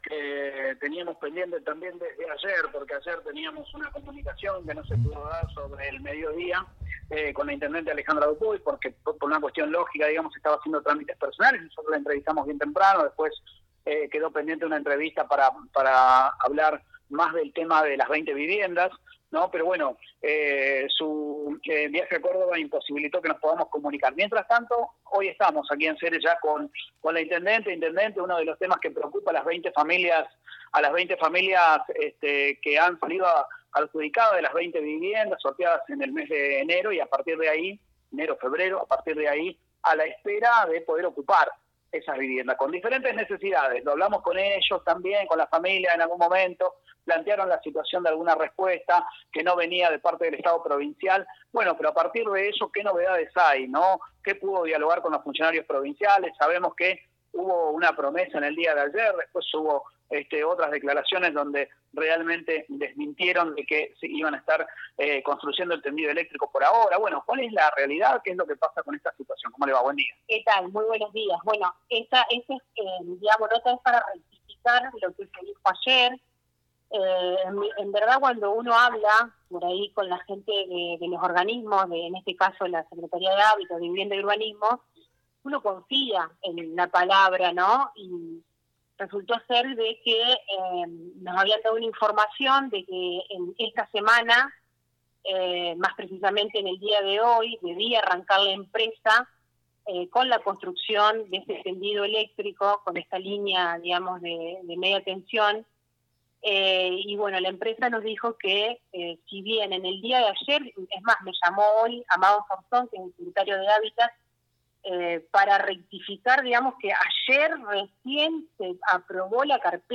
Quien explico la situación fue la Intendente Alejandra Dupouy: